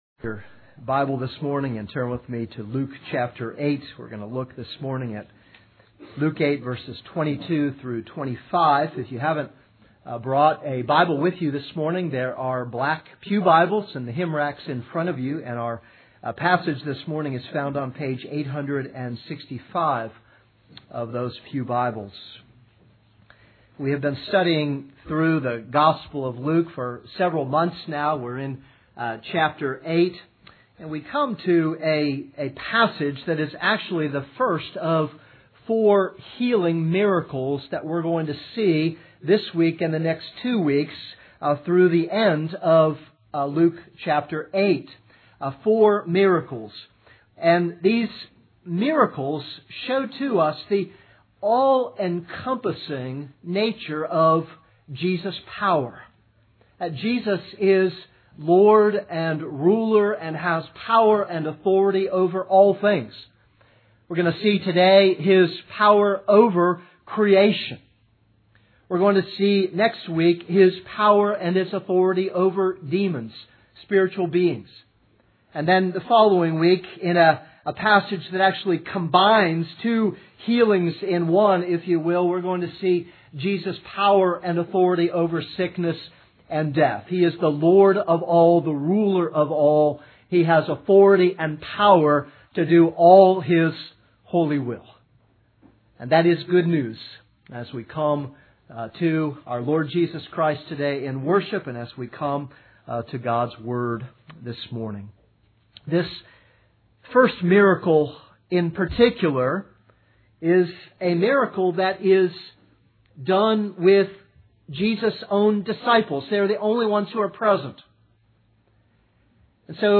This is a sermon on Luke 8:22-25.